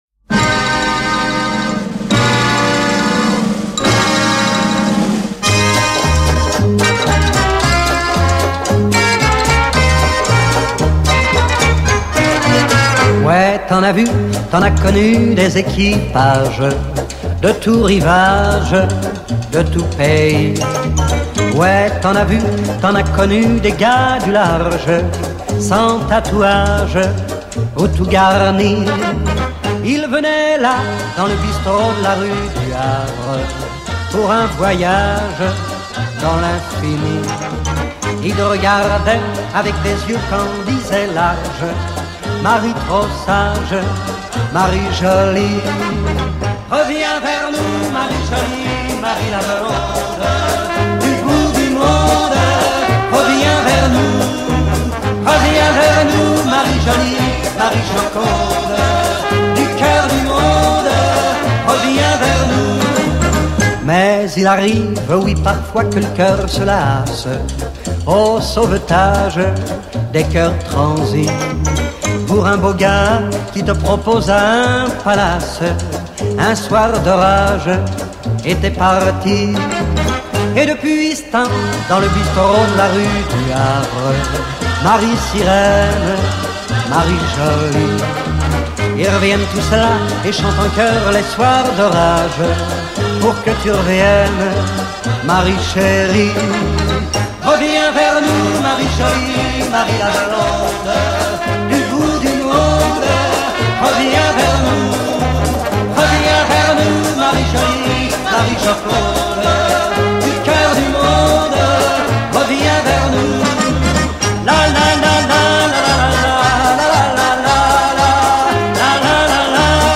Chœur d’hommes fondé en 1860
Partition TTBB
H10142-Live.mp3